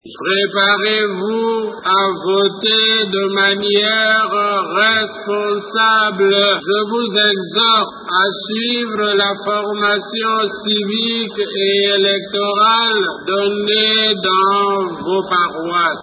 A l’occasion de la fête des rameaux, le cardinal Mosengwo a dit une messe dédiée aux jeunes de l’Église catholique  le dimanche 17 avril au stade des Martyrs de Kinshasa.
Cette messe a réuni 50 000 jeunes.